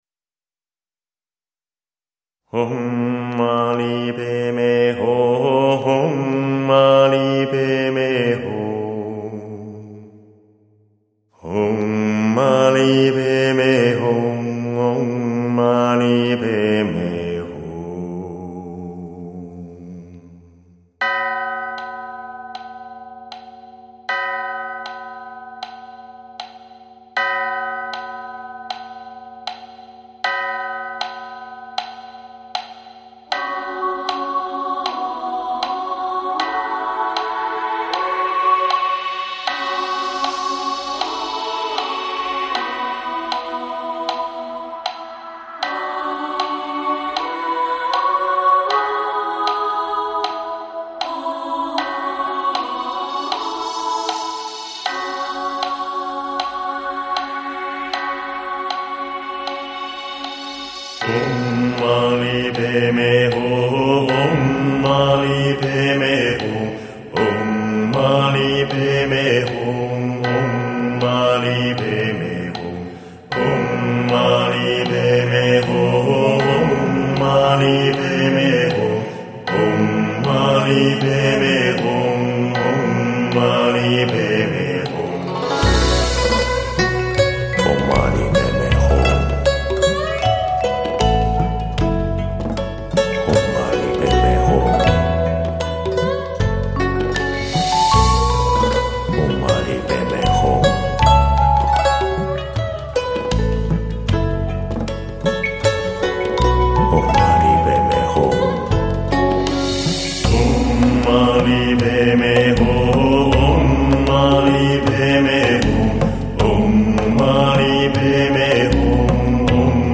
试音碟
这是一盘纯音乐的杂锦合辑，聆听恬静的歌声、回味人生的真谛。
安静祥和的天籁之音。
男女混声唱诵版